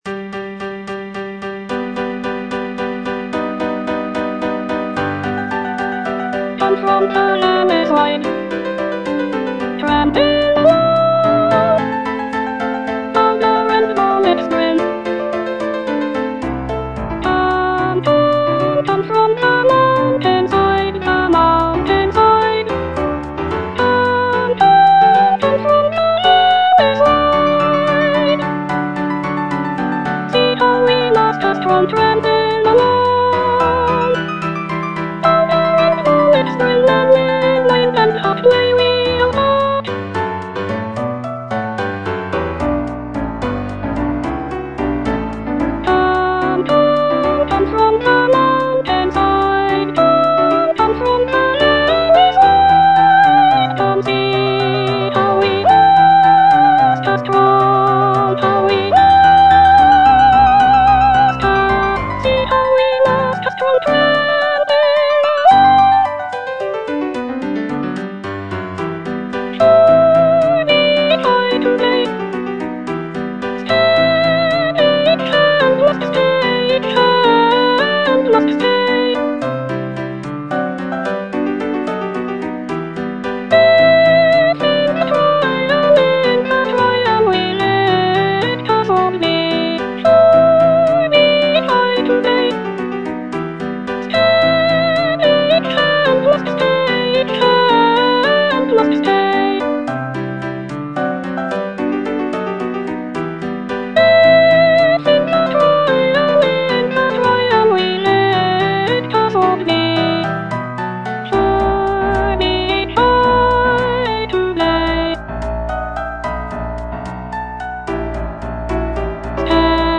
E. ELGAR - FROM THE BAVARIAN HIGHLANDS The marksmen (soprano I) (Voice with metronome) Ads stop: auto-stop
The piece consists of six choral songs, each inspired by Elgar's travels in the Bavarian region of Germany.